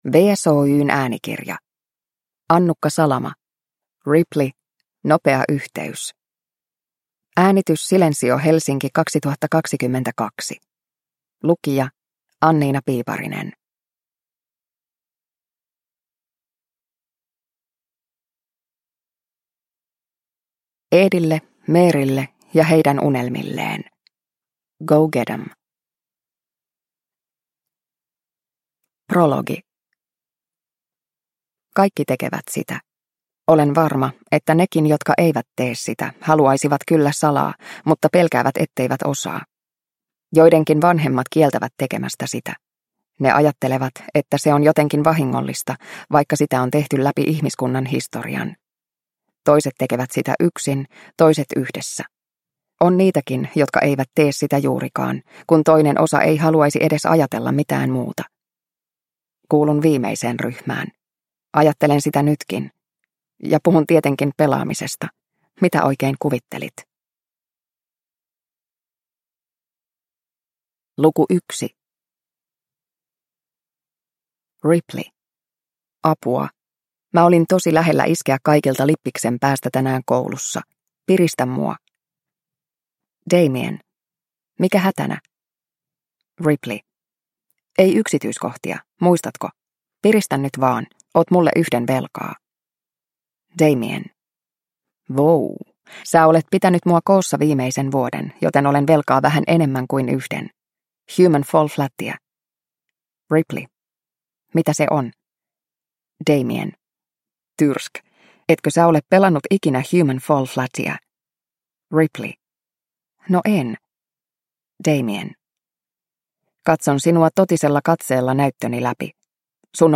Ripley - Nopea yhteys – Ljudbok – Laddas ner